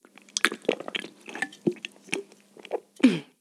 Mujer comiendo
comer
mujer
Sonidos: Acciones humanas
Sonidos: Voz humana